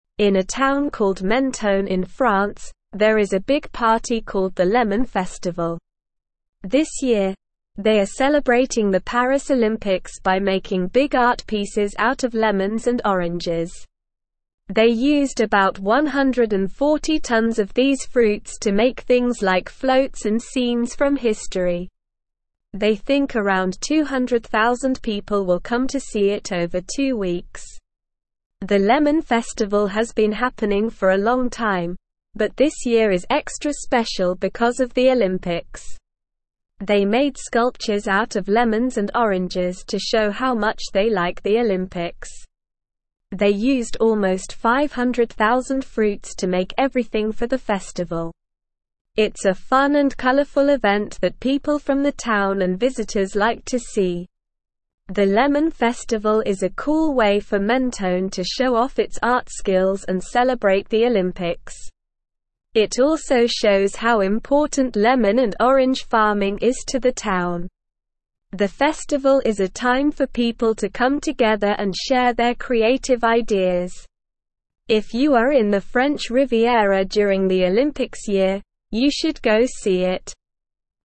Slow
English-Newsroom-Lower-Intermediate-SLOW-Reading-Big-Lemon-Festival-Celebrates-Paris-Olympics-with-Fruit-Art.mp3